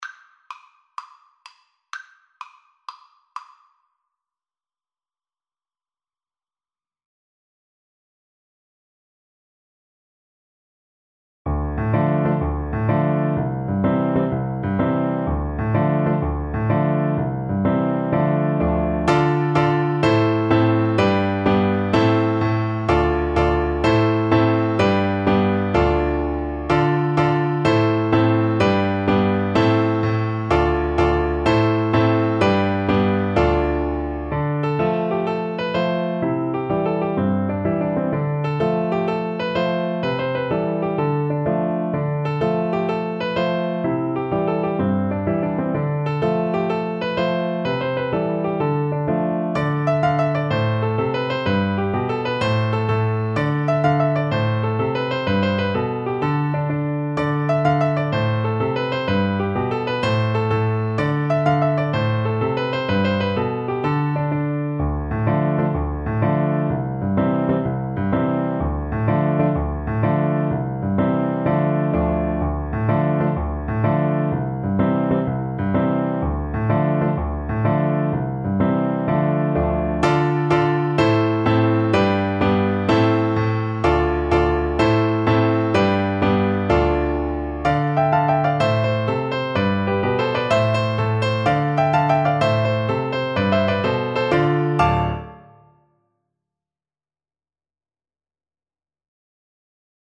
Play (or use space bar on your keyboard) Pause Music Playalong - Piano Accompaniment Playalong Band Accompaniment not yet available reset tempo print settings full screen
12/8 (View more 12/8 Music)
A minor (Sounding Pitch) (View more A minor Music for Recorder )
Fast .=c.126